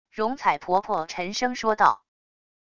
榕采婆婆沉声说道wav音频生成系统WAV Audio Player